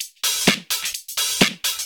Index of /VEE/VEE Electro Loops 128 BPM
VEE Electro Loop 391.wav